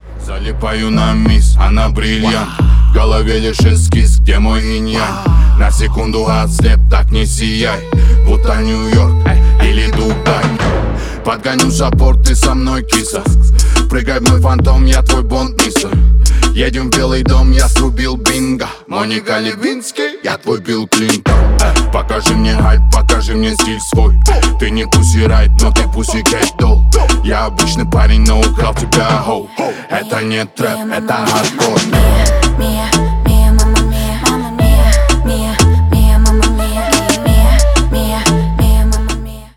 бесплатный рингтон в виде самого яркого фрагмента из песни
Рэп и Хип Хоп